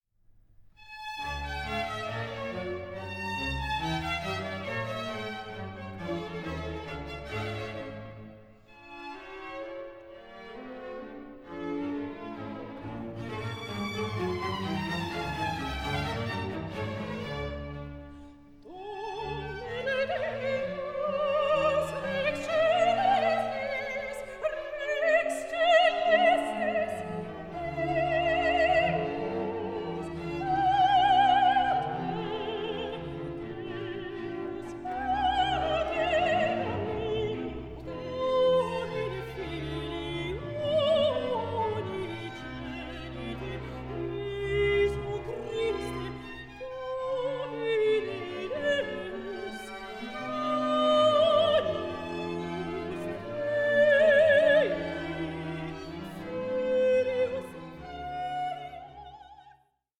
(48/24, 88/24, 96/24) Stereo  14,99 Select
INTIMATE PERFORMANCE
made in connection to live performances
in a chamber-like setting
a small ripieno choir and four outstanding soloists
period-instrument ensembles